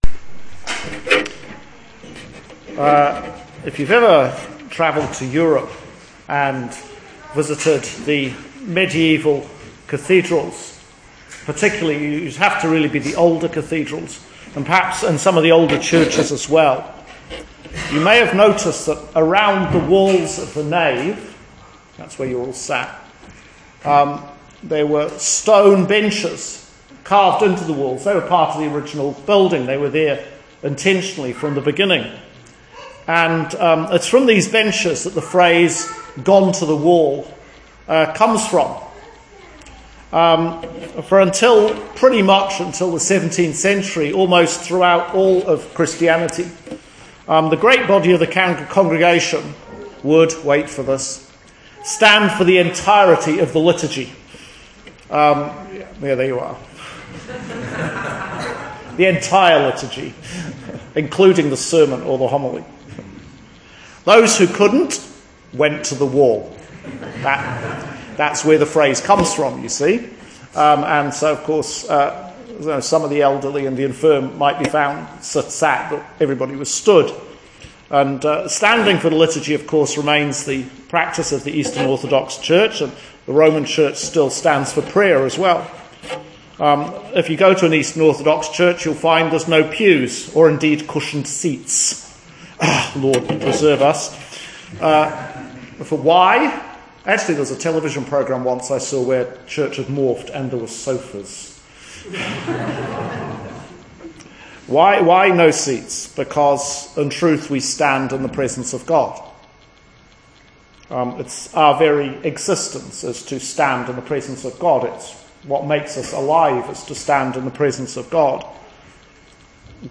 Sermon for Advent Sunday – Year C, 2018